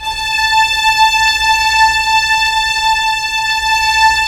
Index of /90_sSampleCDs/Roland LCDP13 String Sections/STR_Violins I/STR_Vls1 Sym slo